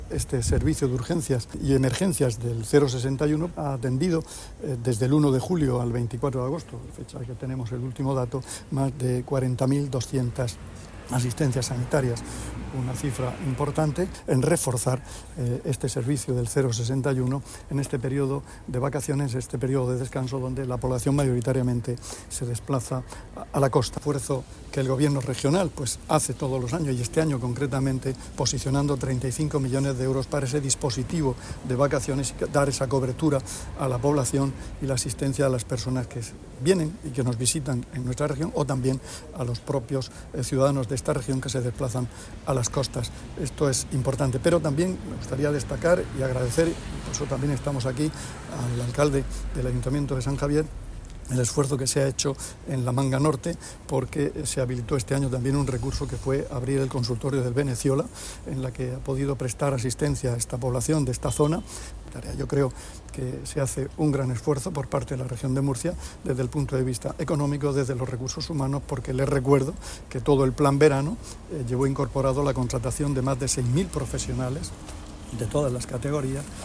Declaraciones del consejero de Salud, Juan José Pedreño, sobre las asistencias realizadas por el 061 en las zonas de costa este verano.
Visita al Servicio de Urgencias de Atención Primaria (SUAP) de San Javier